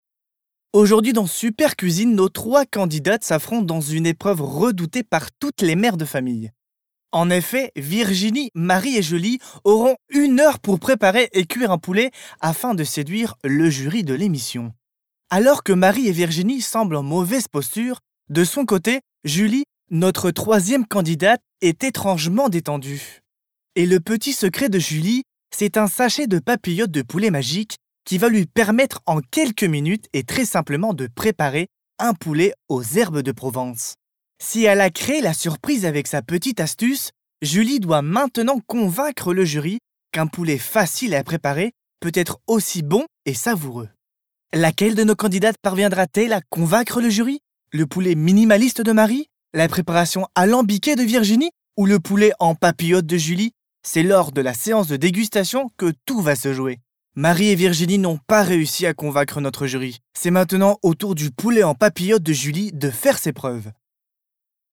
Voix off
Pub AXE